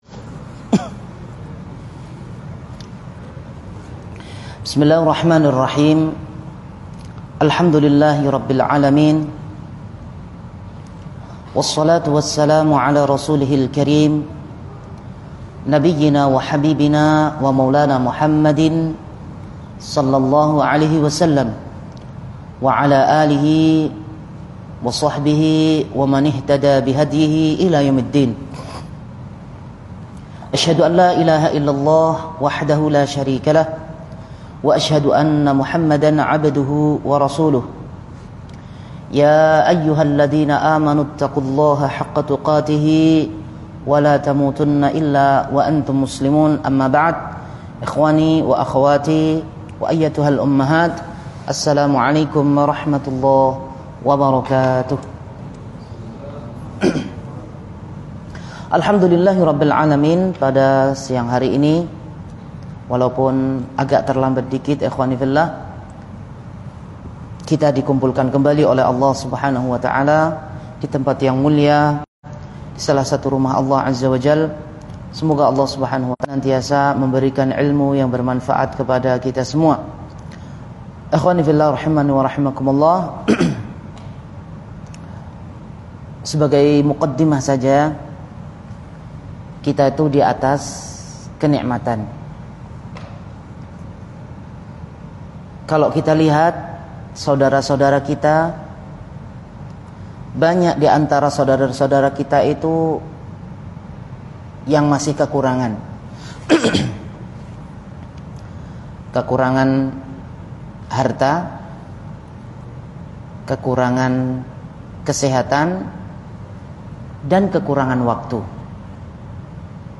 Kajian Sabtu